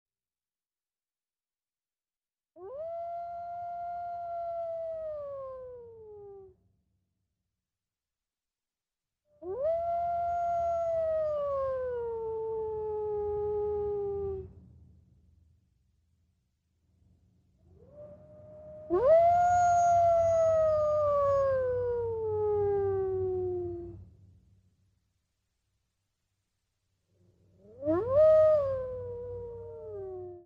Click on the names of some of the animals to hear what they sound like.
coyote